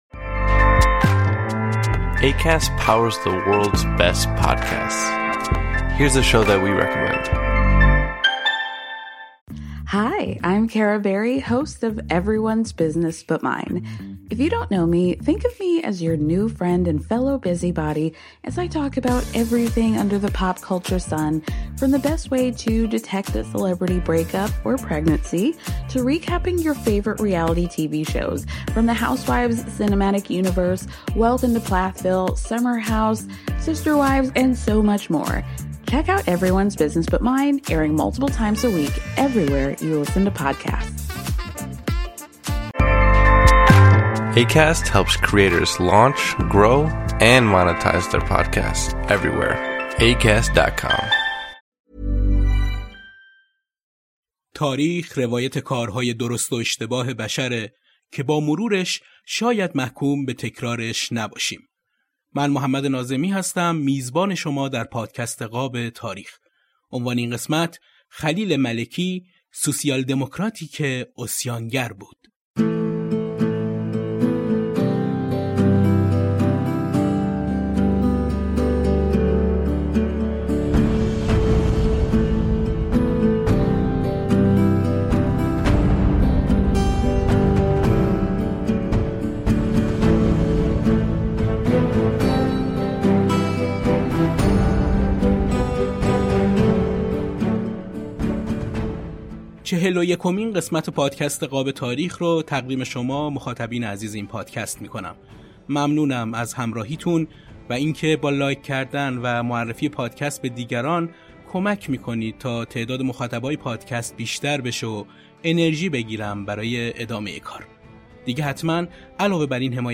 سه‌تار نوازی